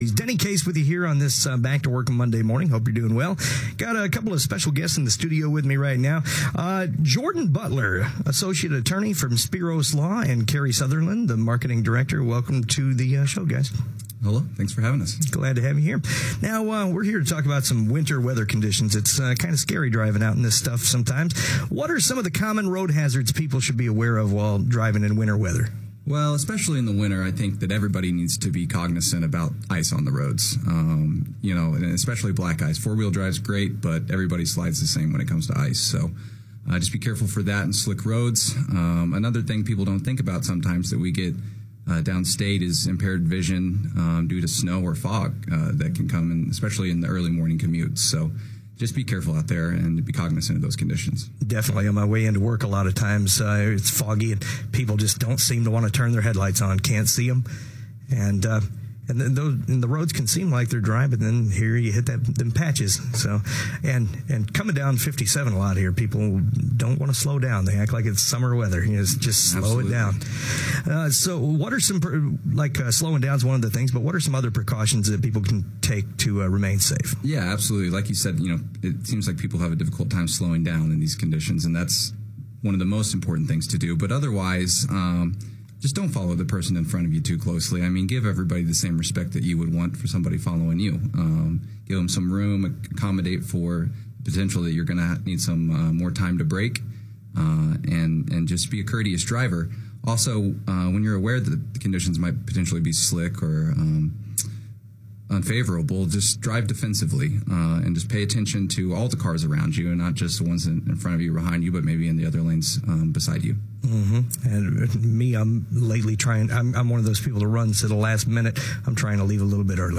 Live on Air